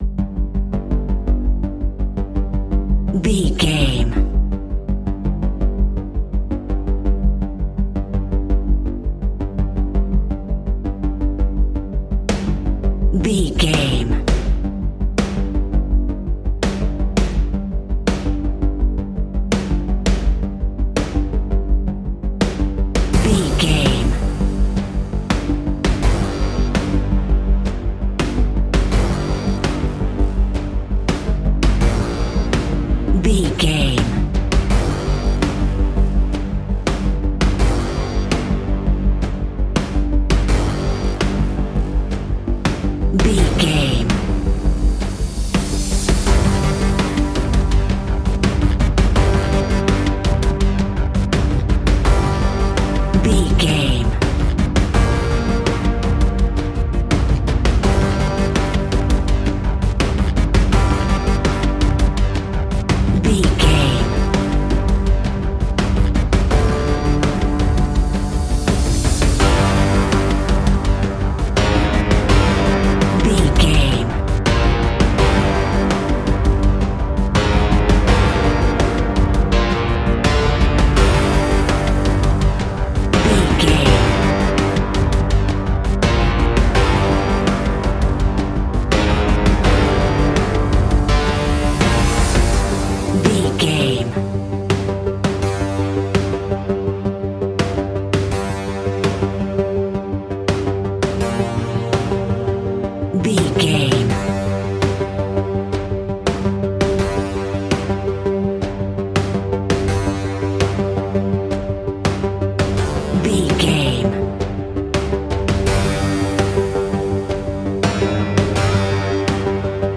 In-crescendo
Aeolian/Minor
ominous
dark
eerie
electronic music
Horror Synths